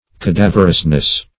cadaverousness - definition of cadaverousness - synonyms, pronunciation, spelling from Free Dictionary